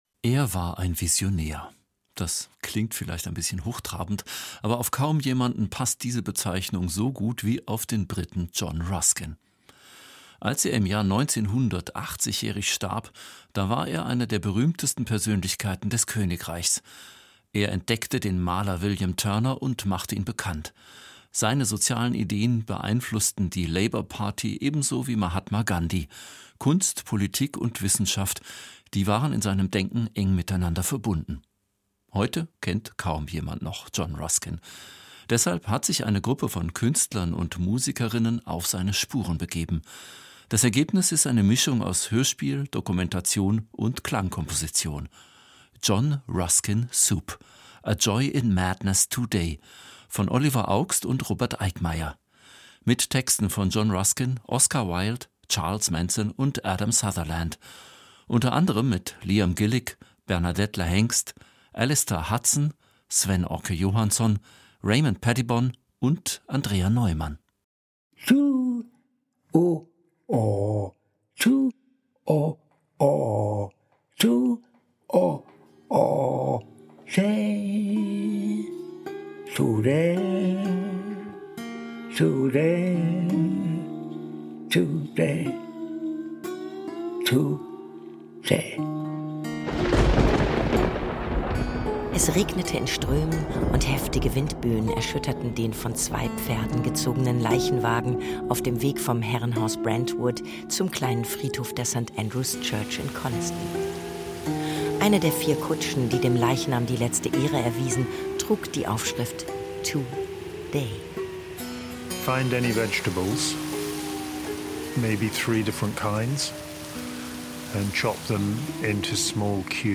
Hörspiel über den Visionär John Ruskin - John Ruskin Soup